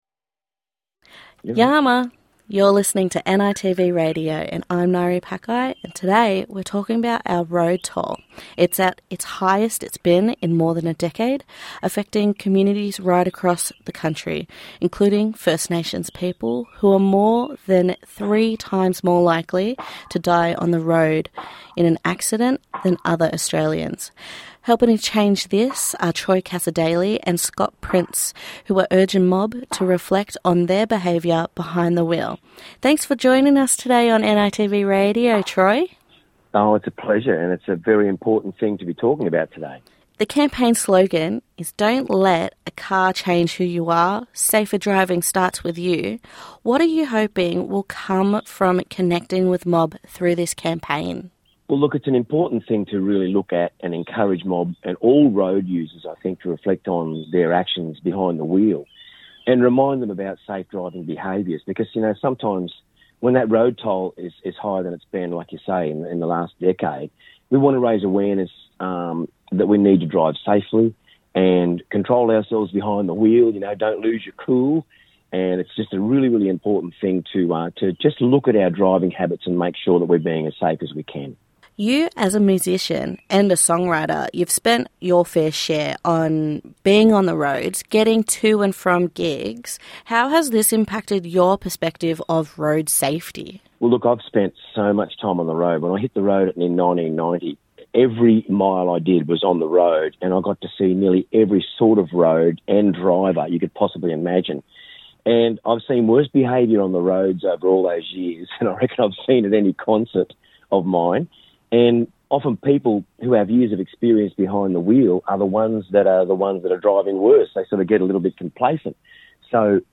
Troy Cassar-Daley talks with NITV Radio about the ‘ Don’t let a car change who you are - Safer driving starts with you’ campaign aimed at getting mob to think about driving and road safety. Australia’s road toll is the highest it’s been in more than a decade and First Nations Australians are three times more likely to die in a road accident than other Australians Troy shares his involvement in the campaign after many years travelling as a touring musician experiencing all kinds of drivers on the roads.